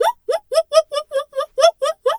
pgs/Assets/Audio/Animal_Impersonations/zebra_whinny_01.wav at master
zebra_whinny_01.wav